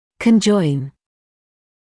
[kən’ʤɔɪn][кэн’джойн]соединять(ся); сочетать(ся)